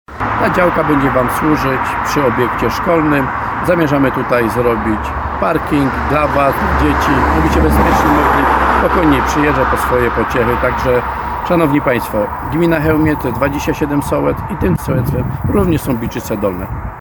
W specjalnym nagraniu zaznaczył, że poprzez takie działania samorząd zaznacza, że interes każdego sołectwa jest ważny.